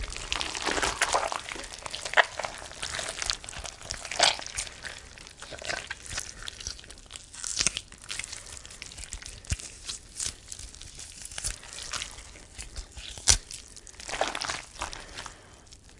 描述：Pumpkin Guts Squish
标签： guts pumpkin squish
声道立体声